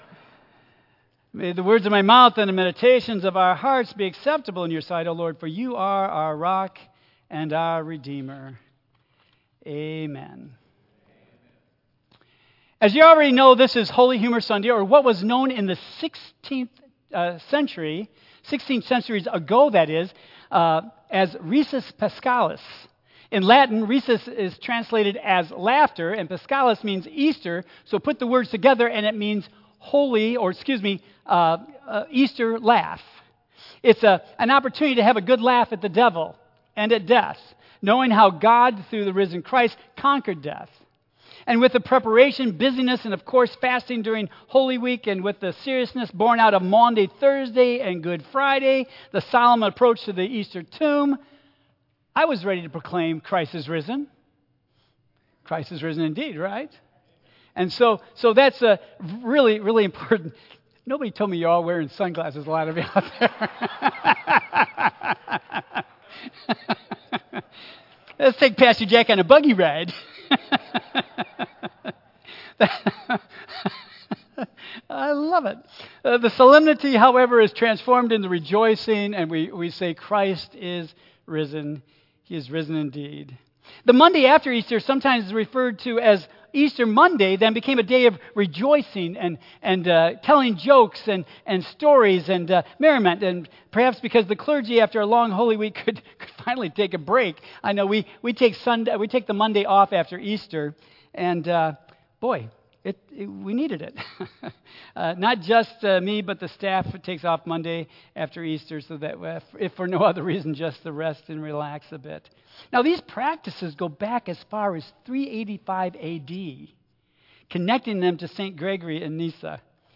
Tagged with Michigan , Sermon , Waterford Central United Methodist Church , Worship Audio (MP3) 8 MB Previous Unexpected Blessings Next The Force of Nature